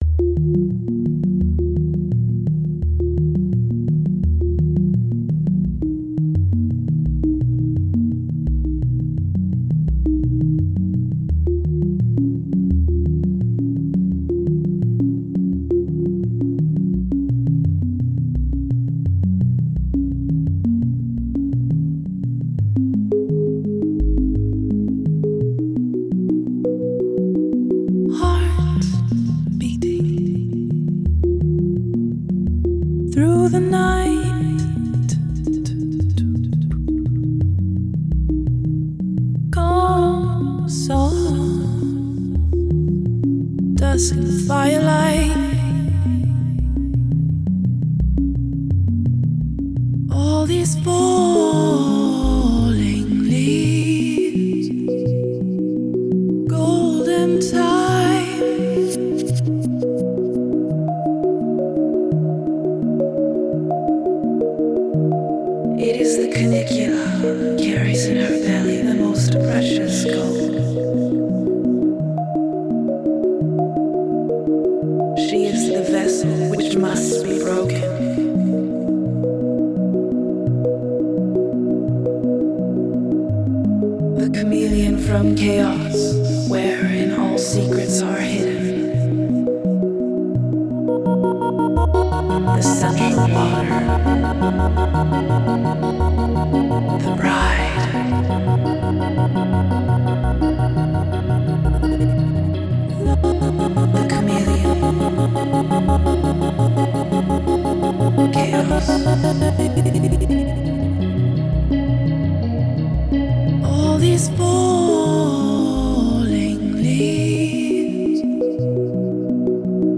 New Age Электронная музыка